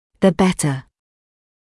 [ðə ‘betə][зэ ‘бэтэ]чем лучше; тем лучше